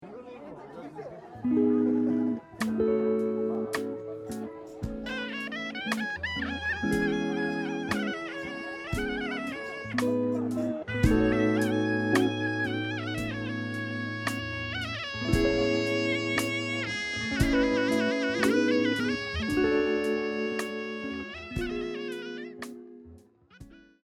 I have had over a week’s time jamming with the tracks that I recorded (see the previous post).
Track 3: The shehnai player nails the blues as the locals gather at the temple to celebrate the success of a village girl, who secured a job at the city.